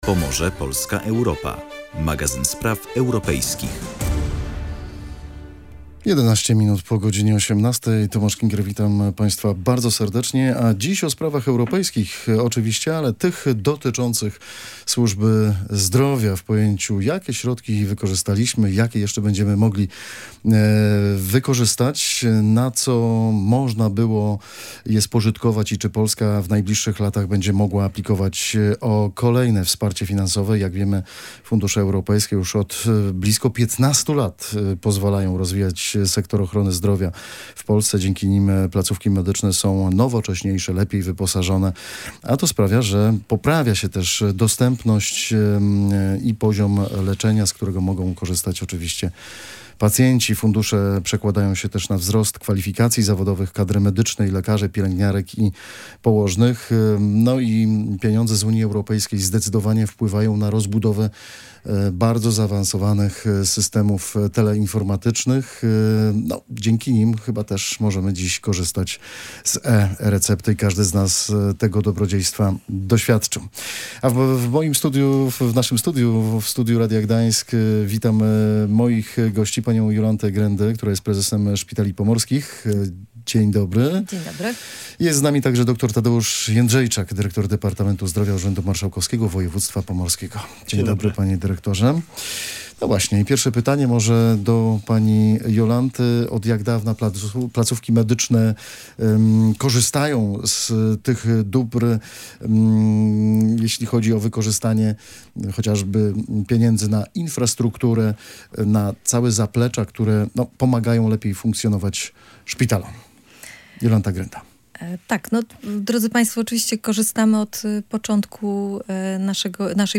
Między innymi na ten temat dyskutowali goście audycji „Pomorze, Polska, Europa”